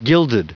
Prononciation du mot gilded en anglais (fichier audio)
Prononciation du mot : gilded